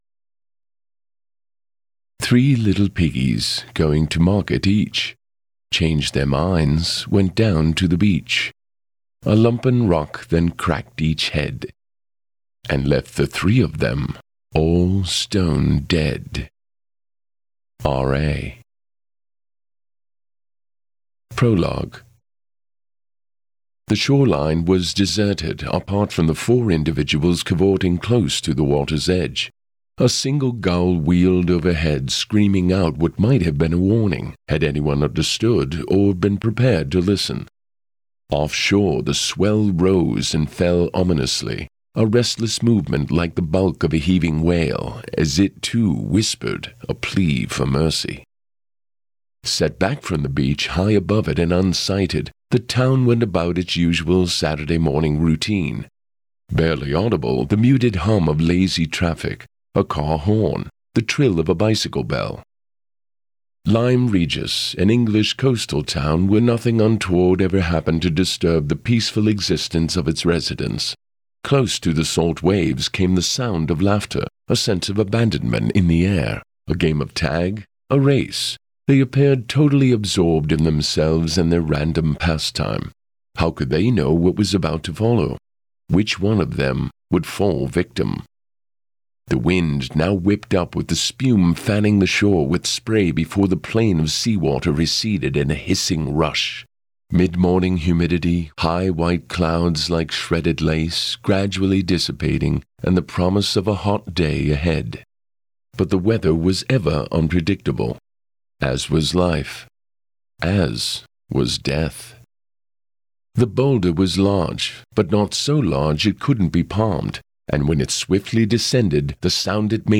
Here’s a sneak peek of – er, listen to – the audiobook edition of Book One in the Tammy Pierre series, The Lyme Regis Murders.